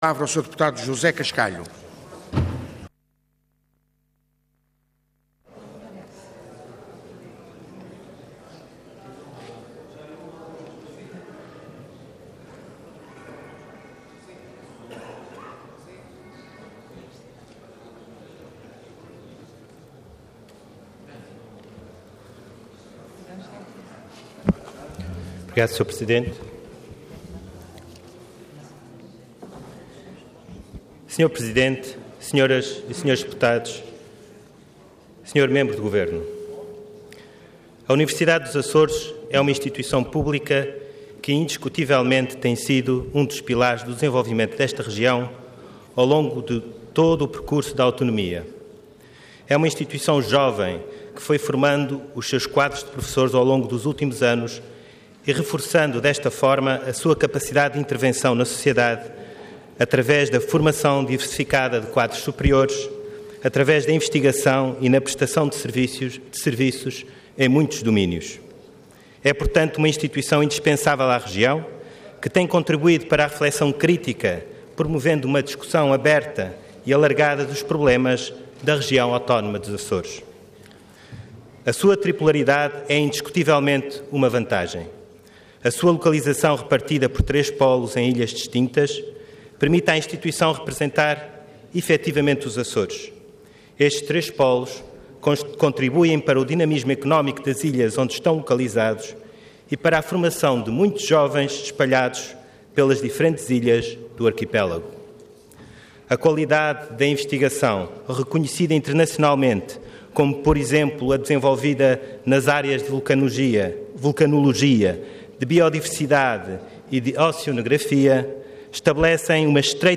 Parlamento online - Intervenção da Deputado José Cascalho do BE
Declaração Política